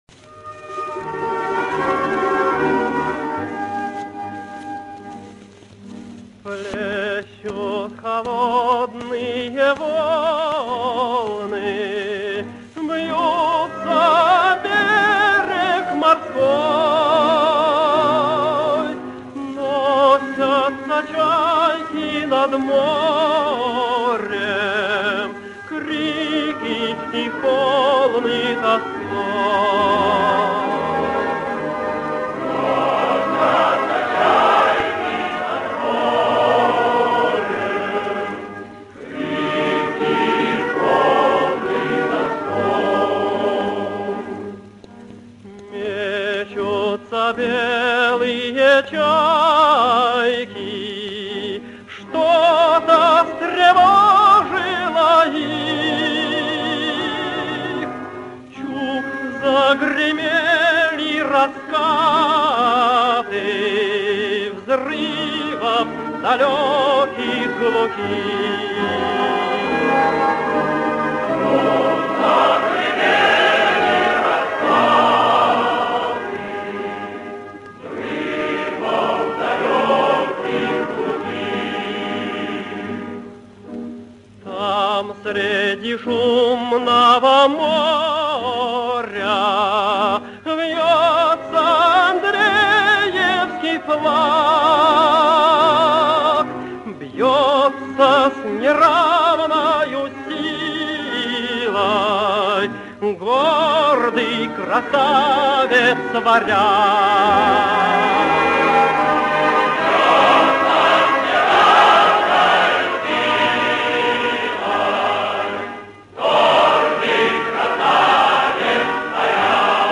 Песня «Плещут холодные волны